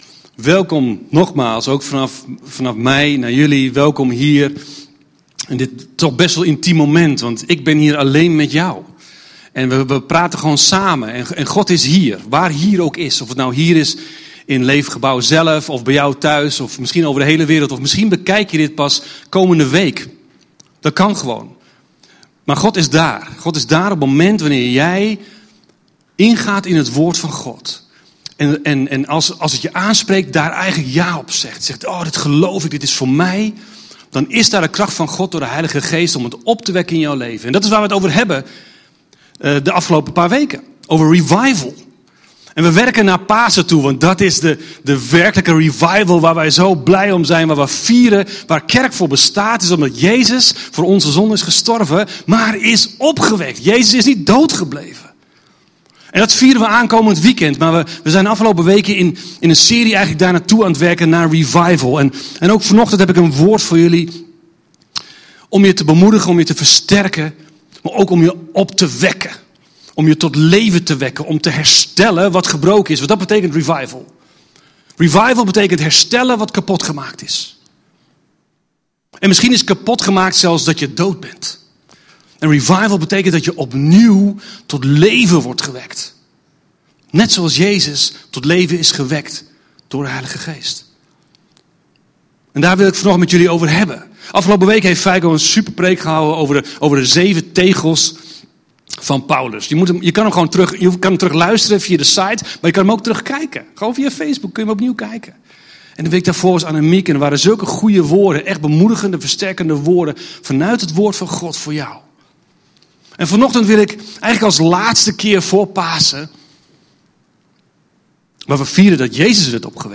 Er worden waardevolle lessen gehaald uit de opstanding van de zoon van de weduwe uit Lucas 7. Luister hier de preek terug!